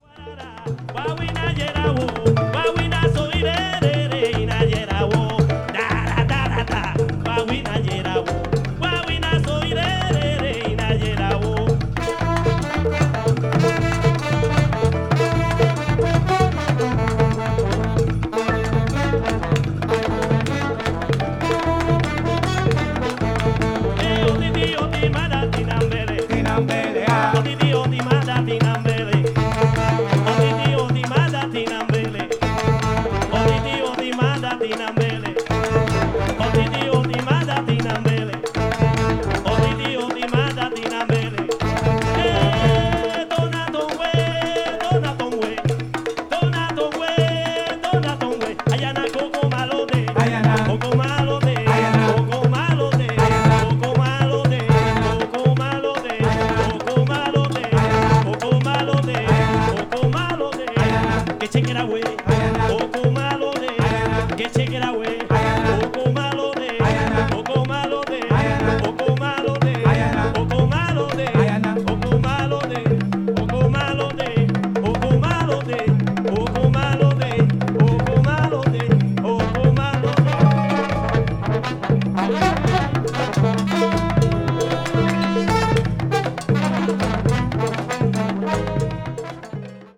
総勢12人のラティーノ達によるビッグ・バンドが繰り出すハイテンションかつエモーショナルなキューバン・ジャズが熱く展開。
ダイレクト・メタル・マスタリングによる臨場感のある音質もイイですね。
ethnic jazz   latin jazz   world music